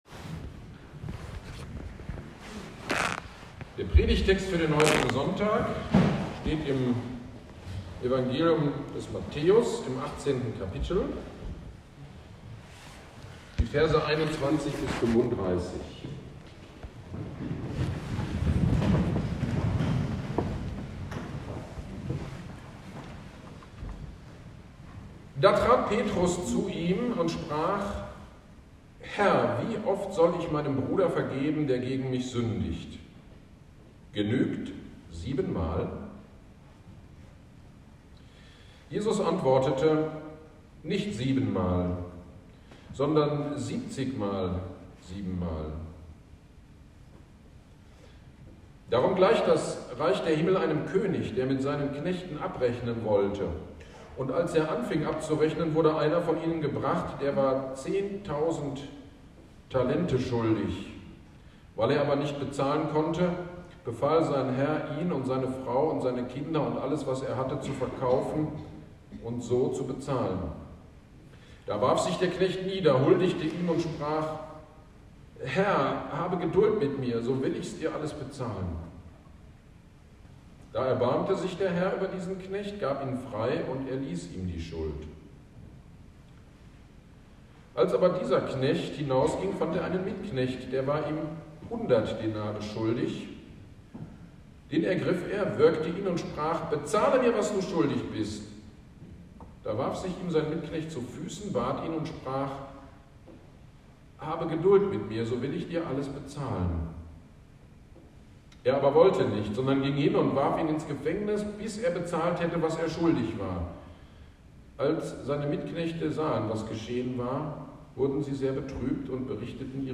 GD am 05.11.23 Predigt zu Matthäus 18.21-35 - Kirchgemeinde Pölzig